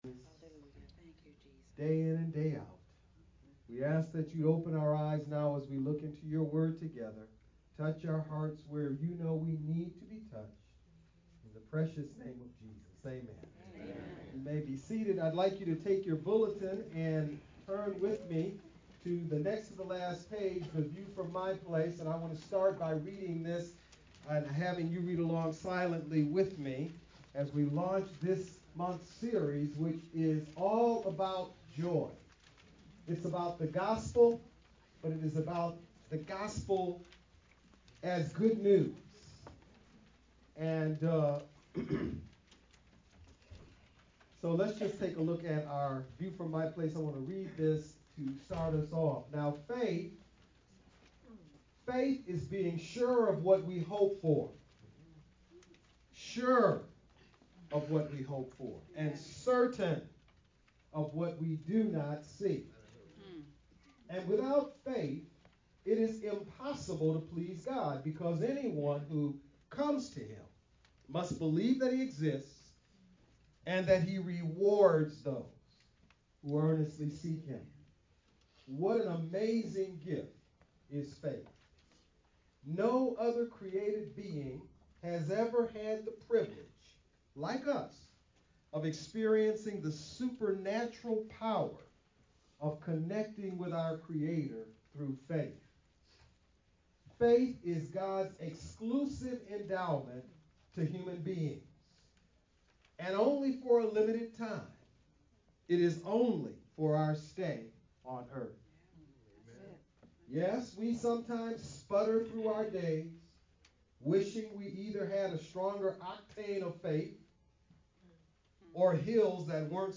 Philippians 3: 1 and Philippians 4:4 Do not allow any earthly event to overshadow the joy of your eternal life in Christ. Message